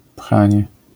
wymowa:
IPA[ˈpxãɲɛ], AS[pχãńe], zjawiska fonetyczne: zmięk.nazal.-ni…